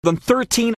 Many of the most common little words of English are usually pronounced with a weak, colourless vowel ‘schwa’, /ə/.